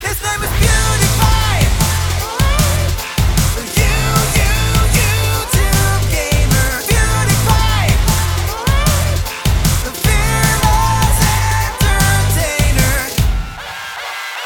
• Качество: 320, Stereo
громкие
зажигательные
веселые